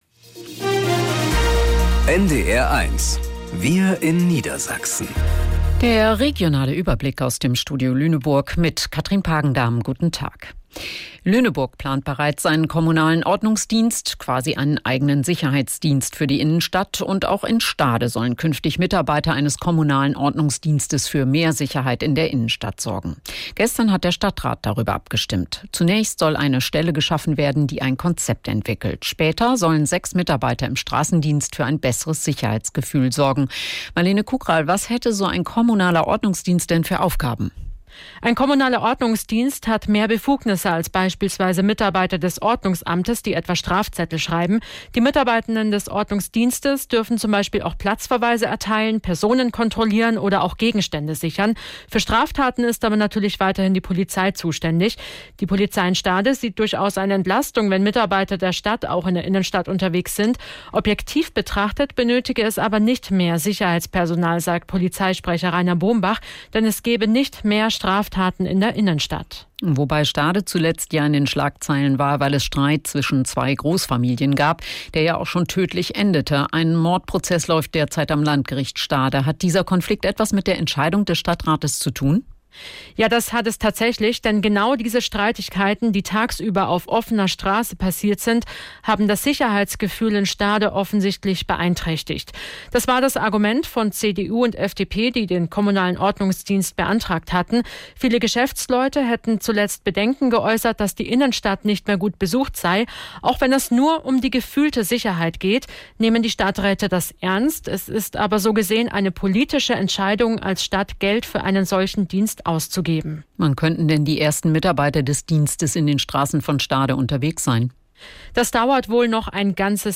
Wir in Niedersachsen - aus dem Studio Lüneburg | Nachrichten